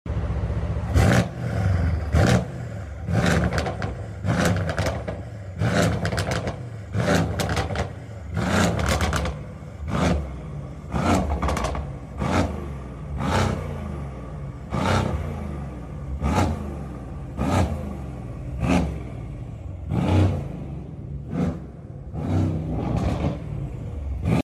Light Rev on the C63S sound effects free download
Light Rev on the C63S AMG Stage 2...